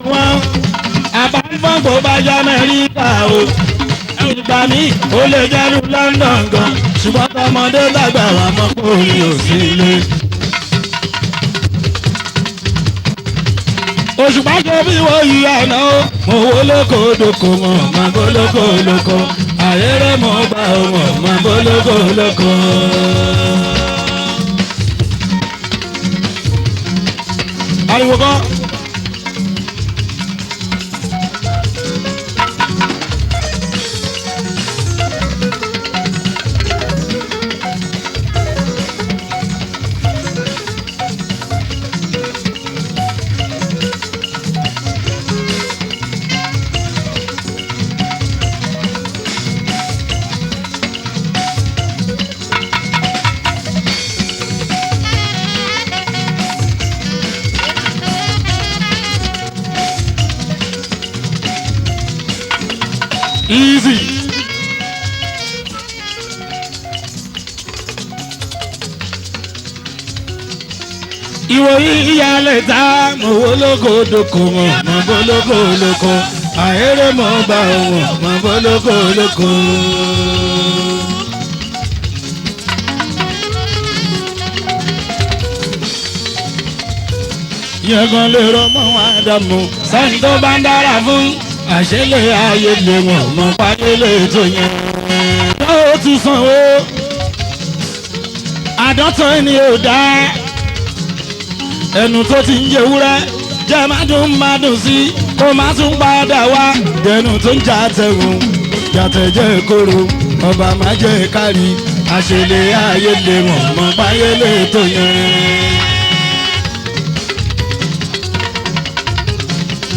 Prolific award winning Fuji Music singer
Yoruba Fuji song